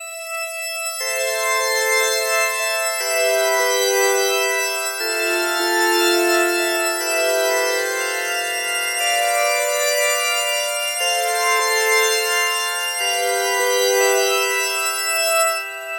描述：氛围、寒意、嘻哈也是...
Tag: 60 bpm Ambient Loops Synth Loops 1.35 MB wav Key : A